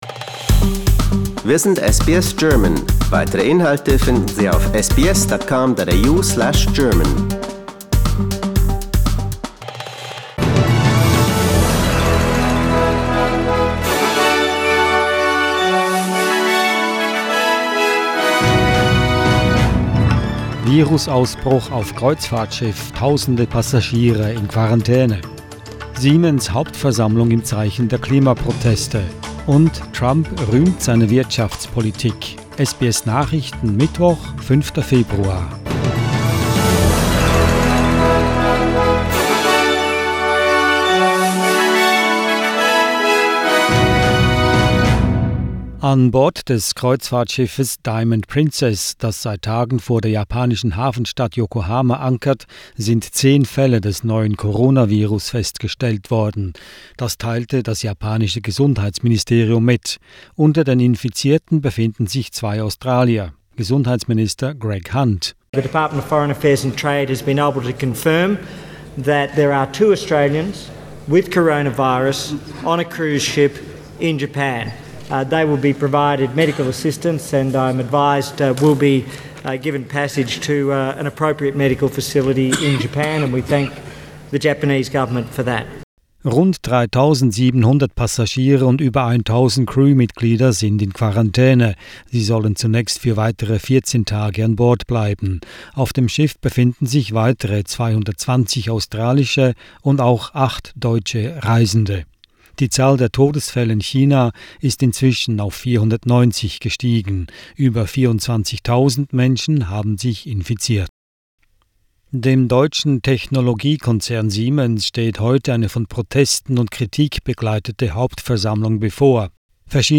SBS Nachrichten, Mittwoch 5.2.20